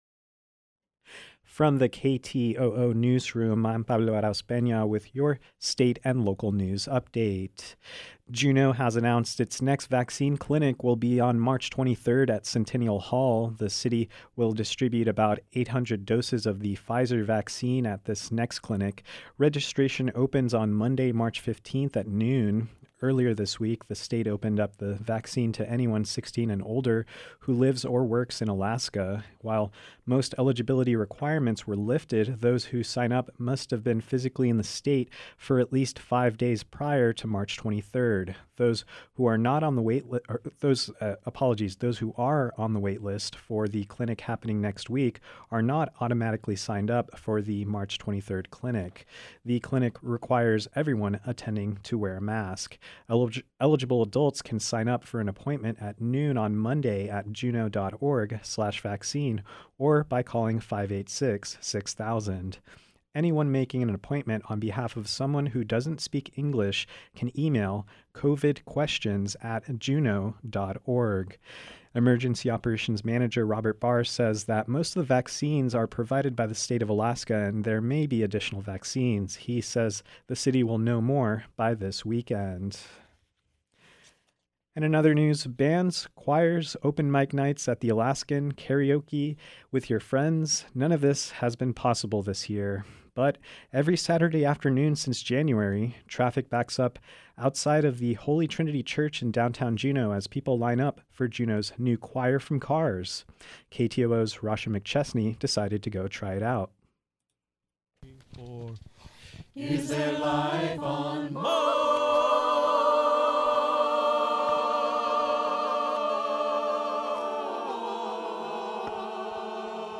Newscast — Thursday, March 11, 2021